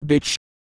Worms speechbanks
ouch.wav